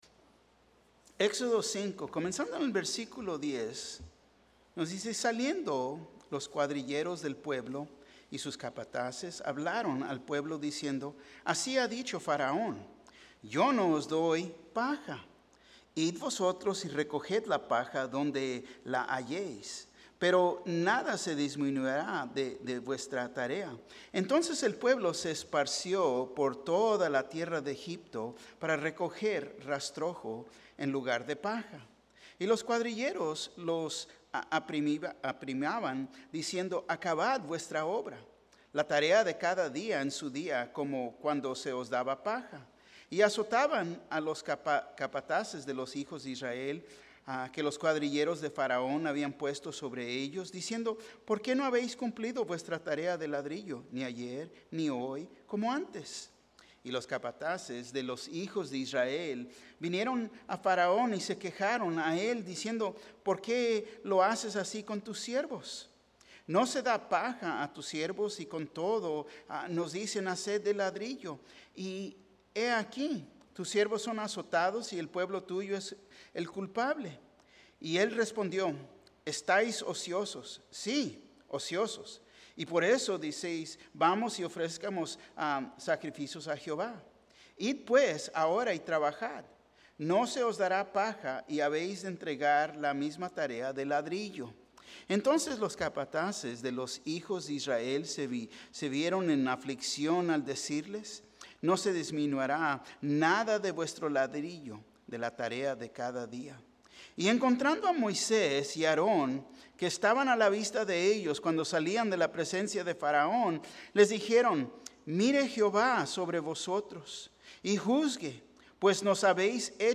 Un mensaje de la serie "Liberados." ¿Crees que Jesús es el mismo Dios de Moisés?